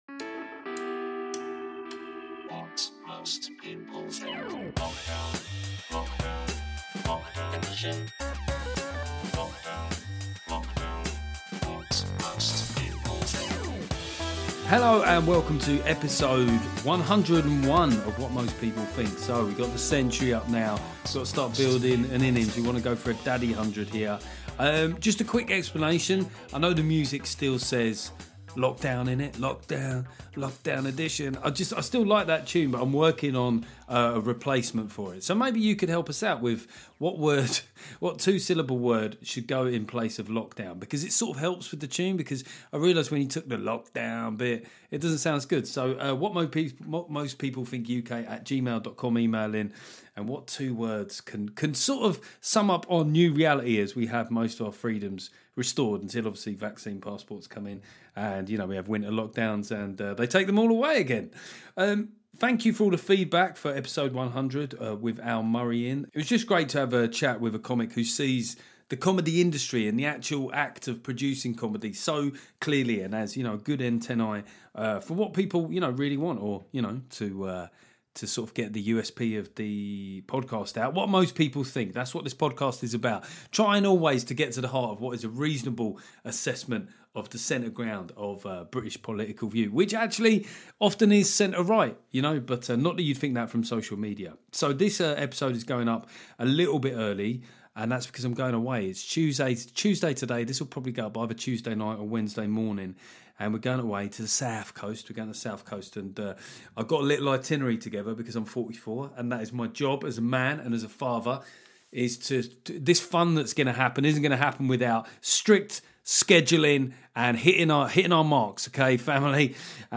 It's one for the purists this week, a solo episode where I look at a frankly astonishing recommendation from an American medical agency on gender. Then we look at the mental health debate in and around sport, trying to determine the difference between real issues and getting the yips.